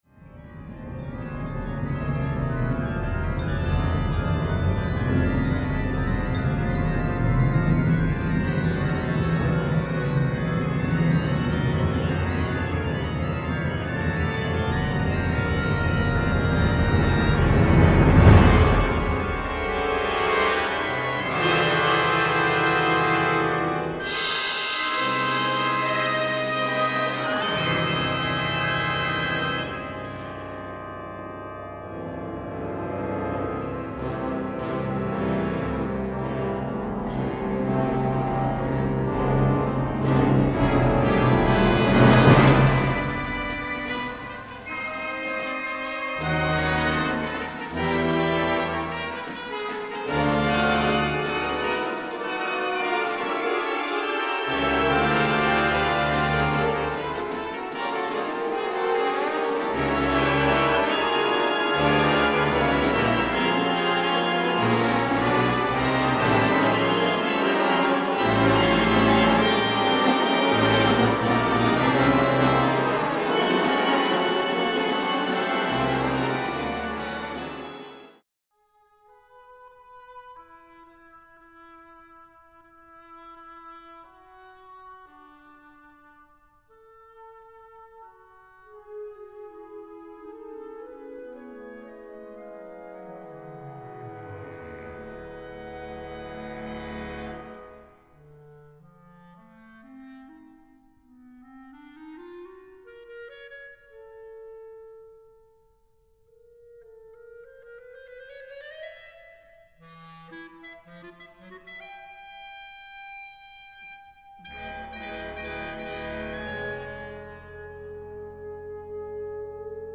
Kategorie Blasorchester/HaFaBra
Unterkategorie Zeitgenössische Bläsermusik (1945-heute)